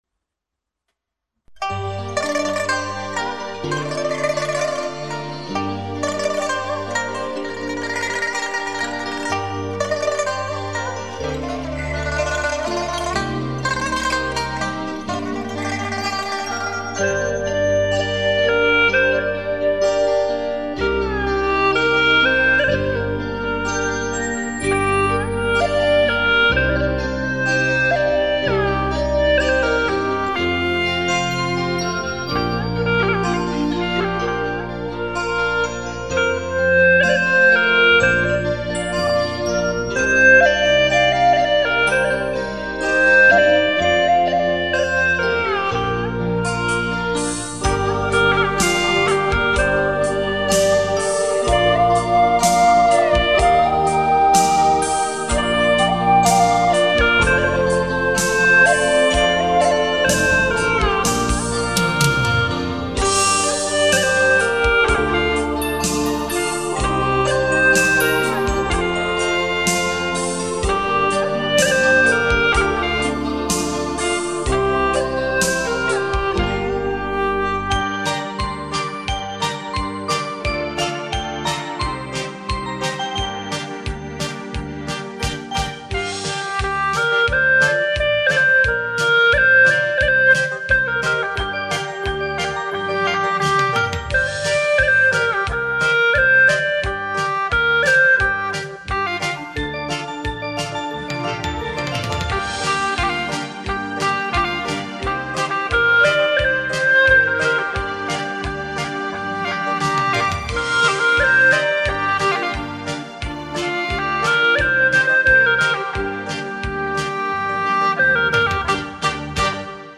调式 : 降B 曲类 : 红歌